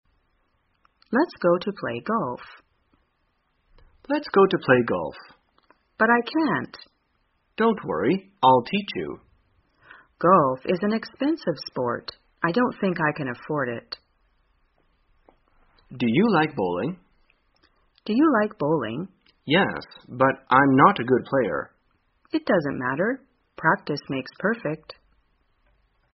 在线英语听力室生活口语天天说 第305期:怎样谈论高尔夫和保龄球的听力文件下载,《生活口语天天说》栏目将日常生活中最常用到的口语句型进行收集和重点讲解。真人发音配字幕帮助英语爱好者们练习听力并进行口语跟读。